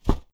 Close Combat Swing Sound 1.wav